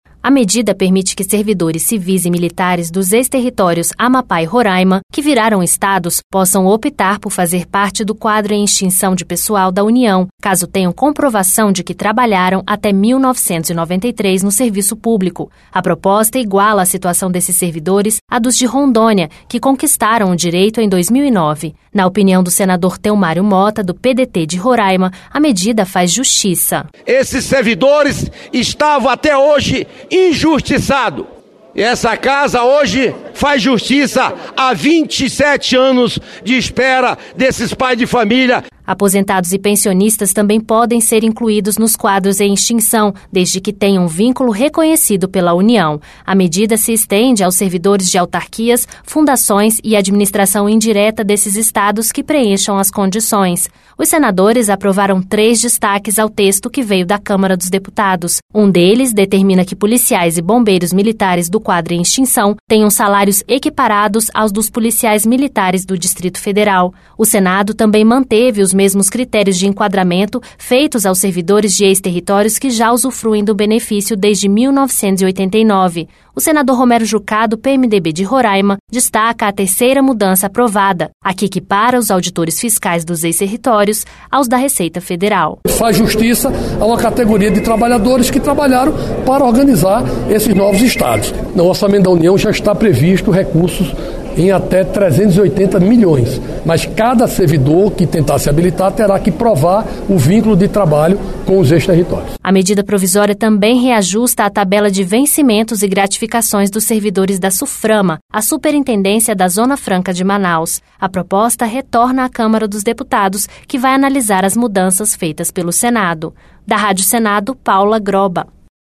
O senador Romero Jucá, do PMDB de Roraima, destaca a terceira mudança aprovada: a que equipara os auditores fiscais dos ex-territórios aos da Receita Federal.